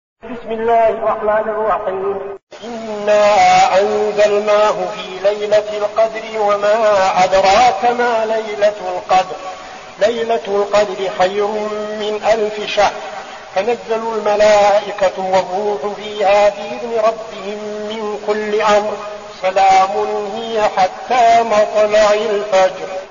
المكان: المسجد النبوي الشيخ: فضيلة الشيخ عبدالعزيز بن صالح فضيلة الشيخ عبدالعزيز بن صالح القدر